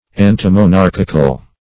Search Result for " antimonarchical" : The Collaborative International Dictionary of English v.0.48: Antimonarchic \An`ti*mo*nar"chic\, Antimonarchical \An`ti*mo*nar"chic*al\, Opposed to monarchial government.